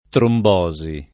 trombosi [ tromb 0@ i ] s. f.